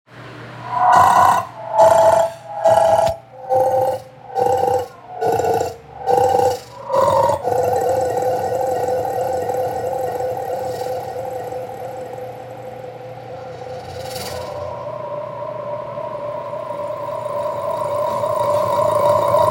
monsters.mp3